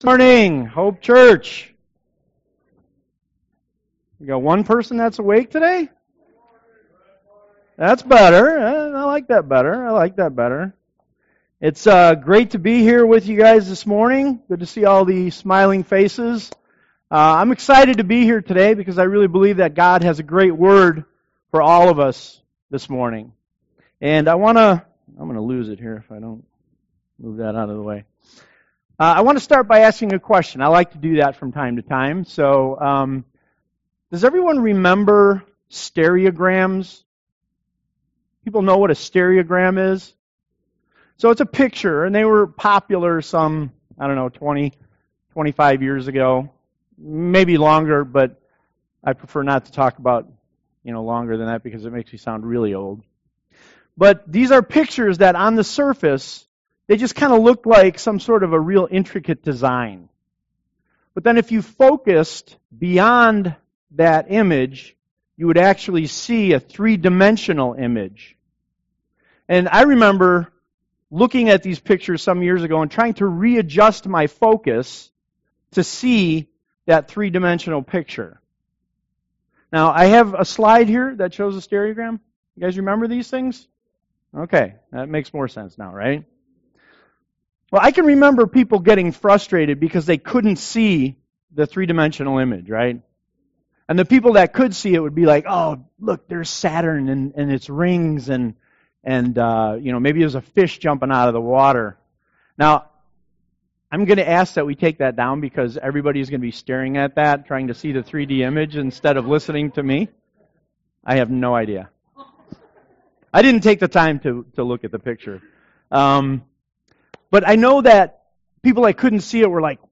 Sermons Archive - Hope Community Church Of Lowell